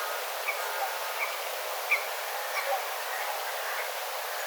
kilpikanan ääntelyä